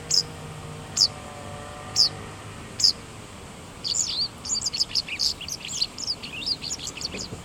だから毎日チェックしているのだが、今日はそこでセグロセキレイの幼鳥を見かけた。
その幼鳥、今日はサービスよくて？さえずりまで聞かせてくれた。
さえずりはココ（8秒弱）
セグロセキレイ　幼鳥